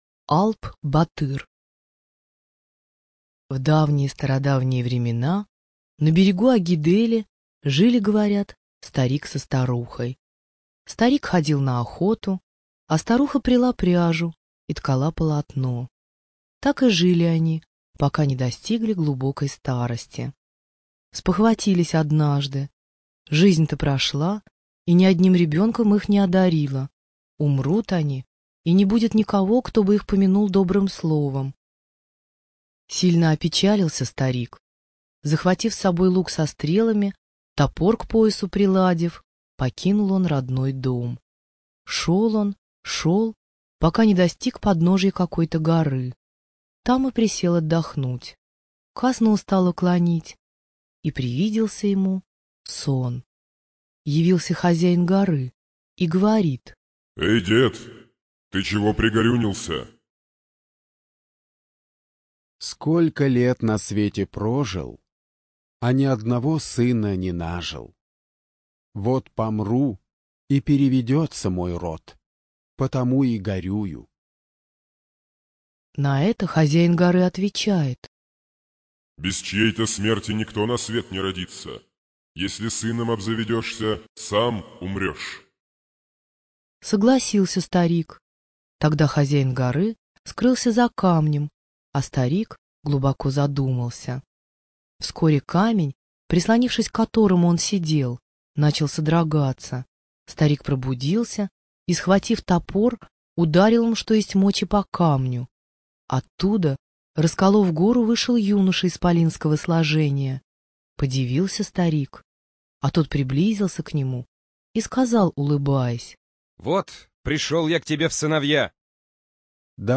ЖанрДетская литература, Сказки
Студия звукозаписиВира-М